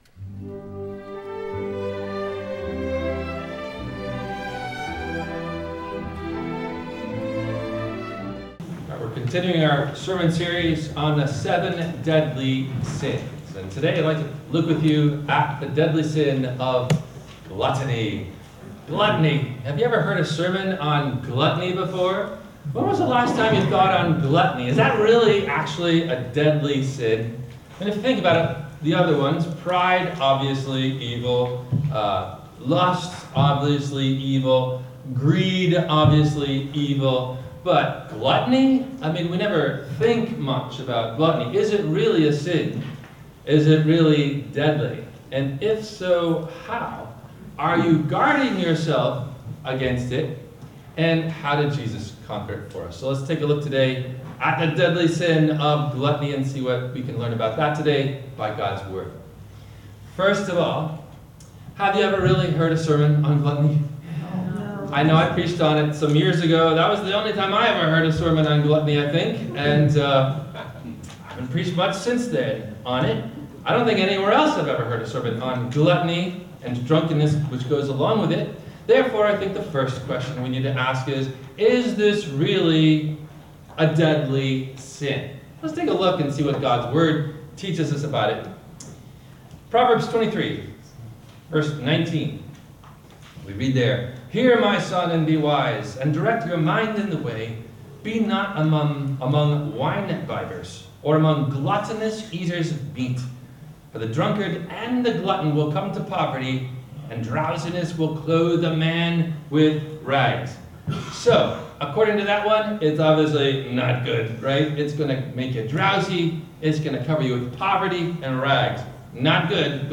Are You Guarding Against…Gluttony? – WMIE Radio Sermon – April 07, 2025